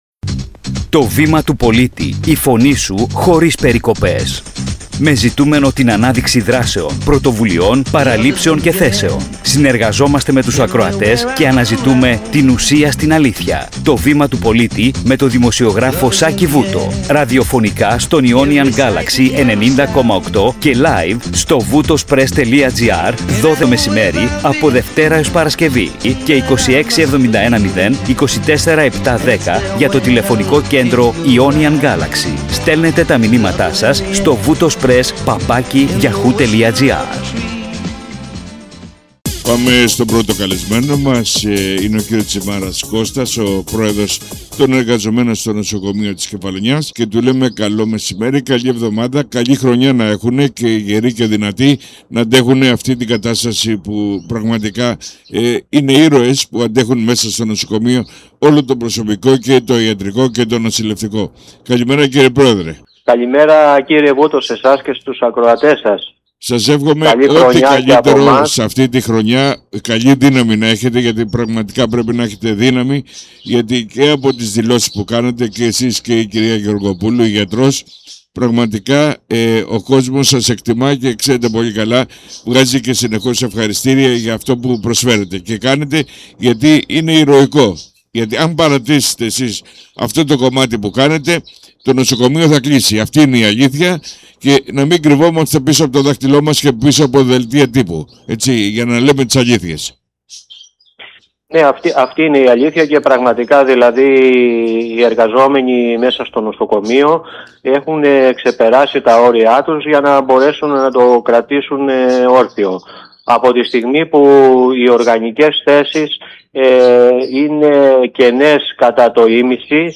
Ραδιοφωνική εκπομπή